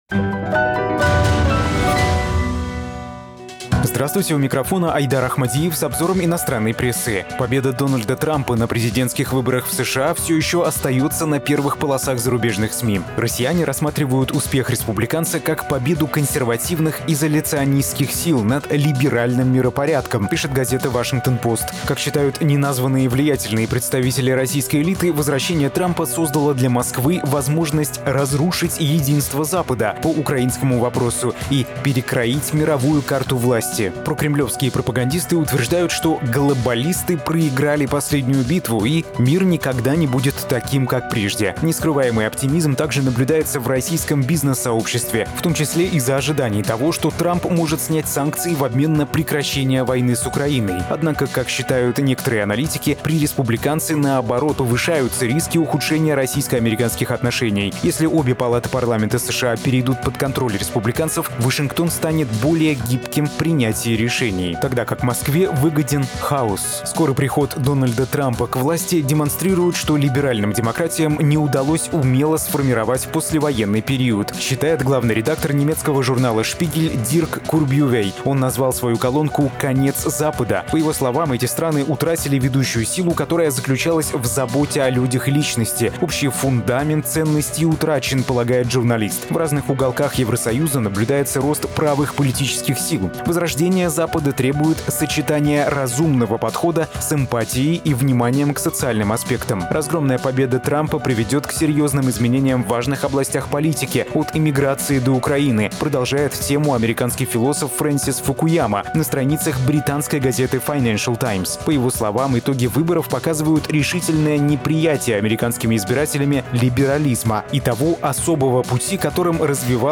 Читаем иностранную прессу.